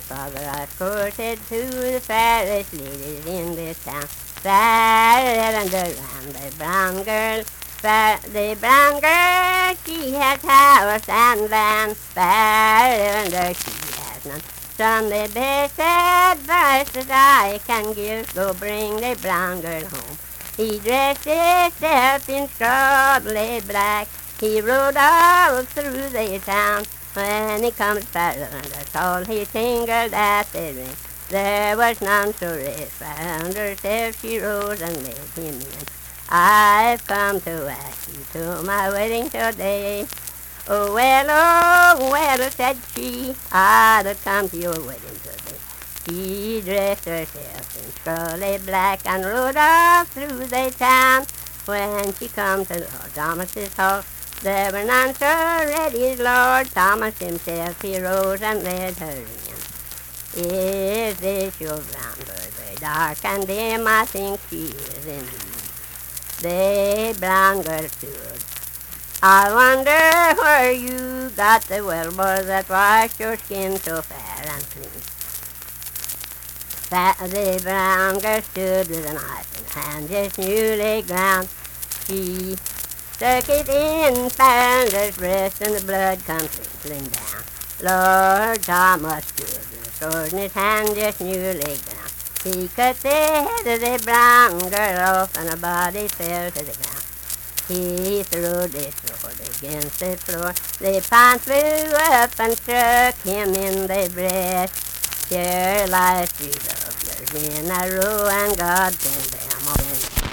Unaccompanied vocal music
Voice (sung)
Logan County (W. Va.), Lundale (W. Va.)